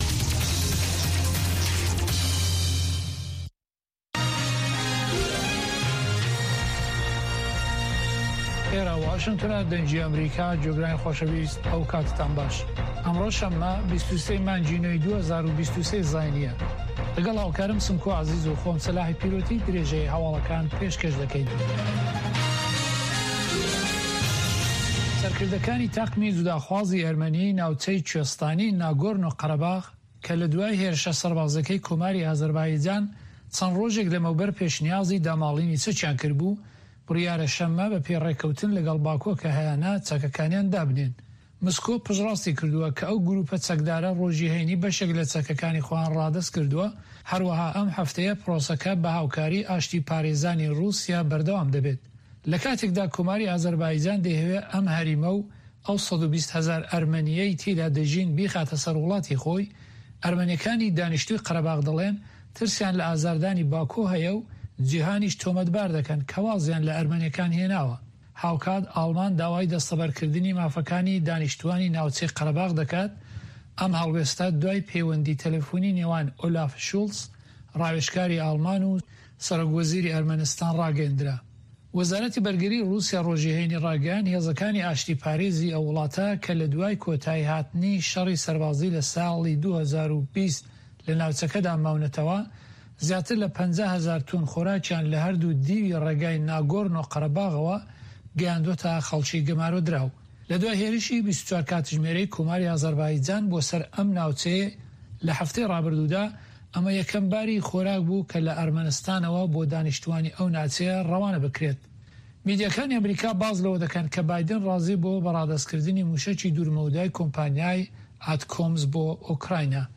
هەواڵە جیهانییەکان 1
هەواڵە جیهانیـیەکان لە دەنگی ئەمەریکا